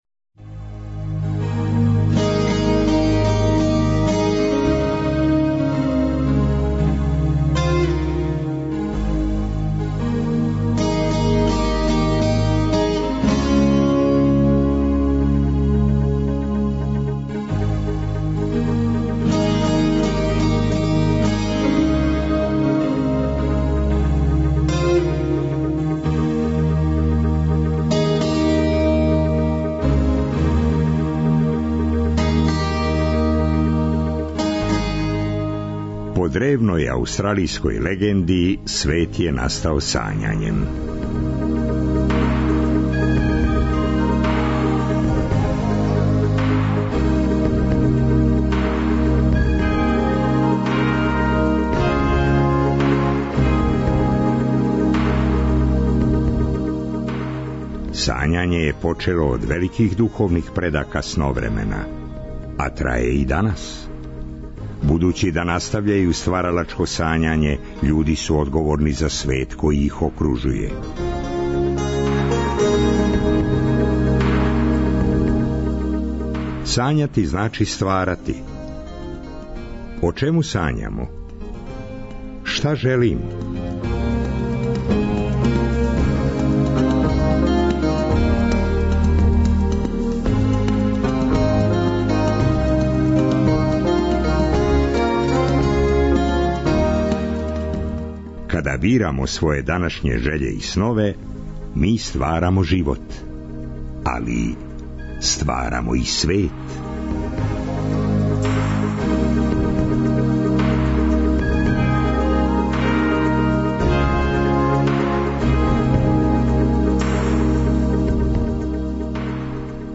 Наша путовања ипак не престају: уместо договореног разговора, уживаћемо у доброј музици. А ту је, наравно, и непресушно "море прича"...